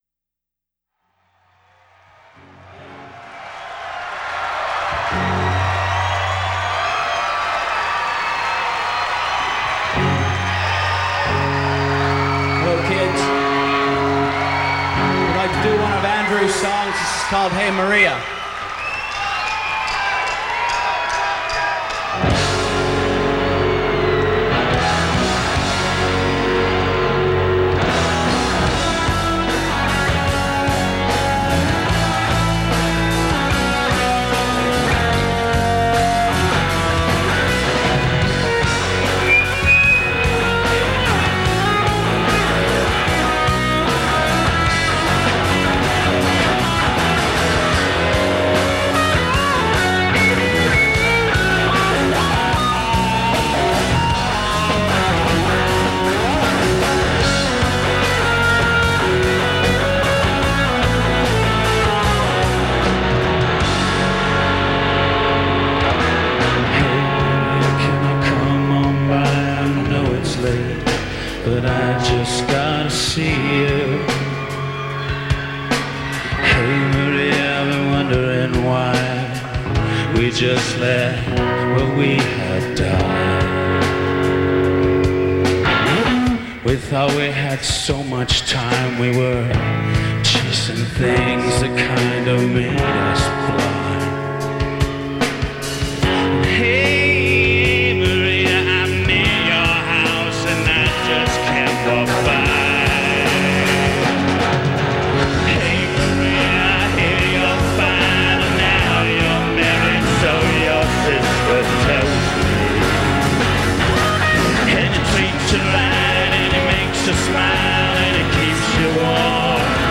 Source: SBD/TV